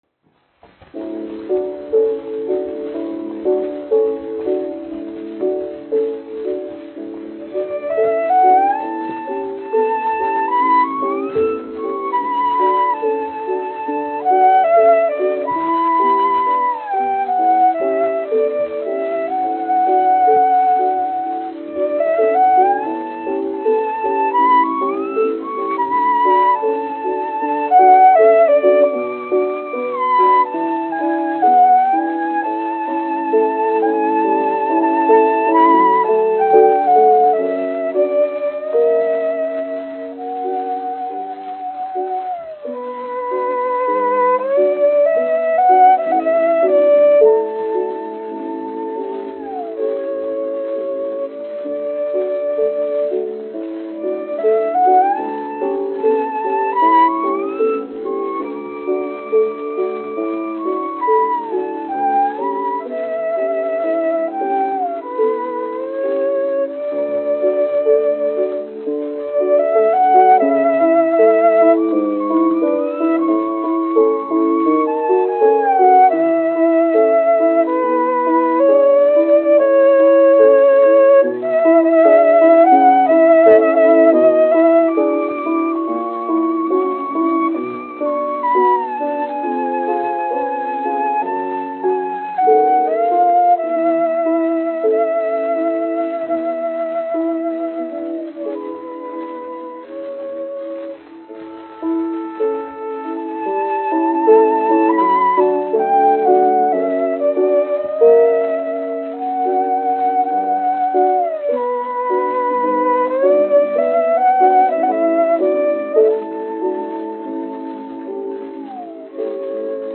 1 skpl. : analogs, 78 apgr/min, mono ; 25 cm
Vijoles un klavieru mūzika
Skaņuplate